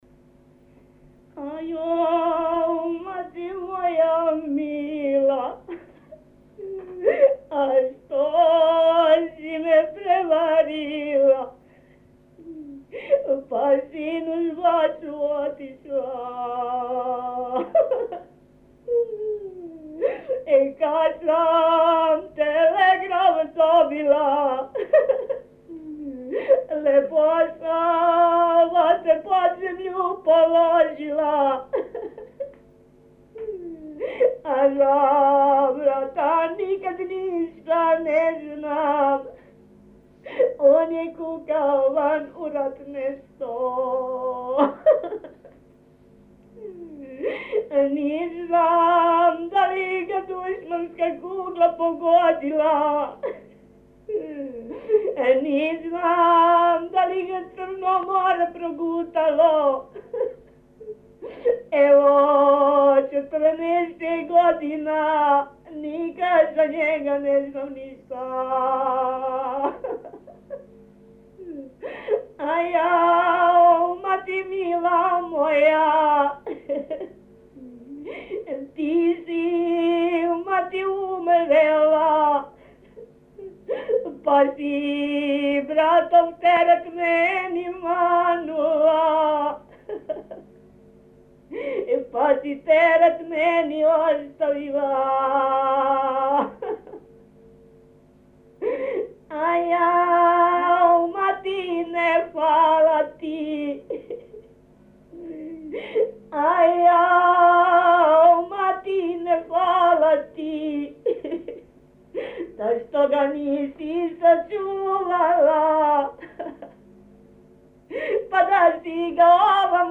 Dialektus: D
Helység: Magyarcsanád
Megjegyzés: Sirató (lány az édesanyját). A hangfelvételen jól hallhatóak az érzelmi kitörések és a jajgatások a dallamstrófák között.